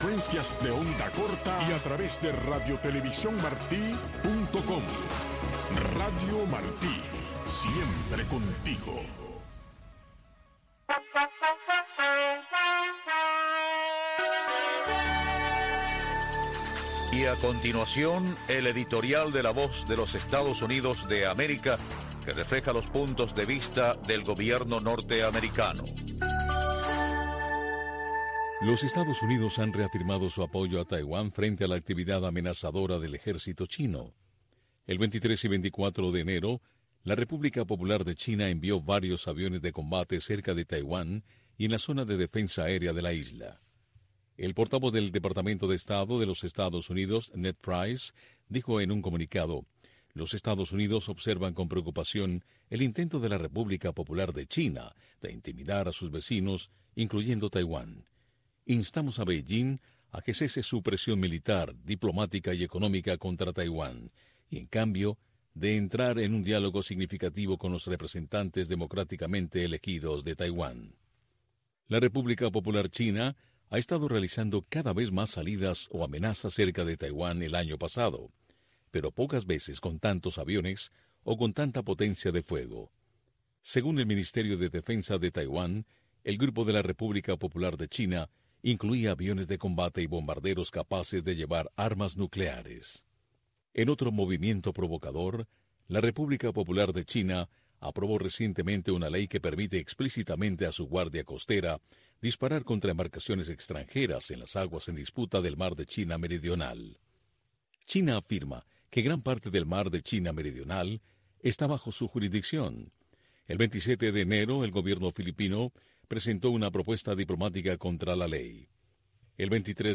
revista de entrevistas